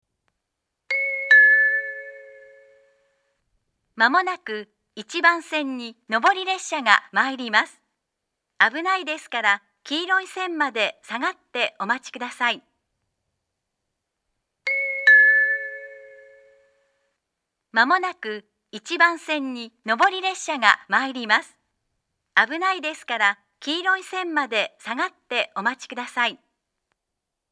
接近放送は首都圏でもよく聞ける東海道型の放送です。
２０１２年９月中旬ごろに放送装置が更新され、自動放送、発車メロディー、スピーカーが変更になりました。
１番線接近放送
shirakawa-1bannsenn-sekkinn1.mp3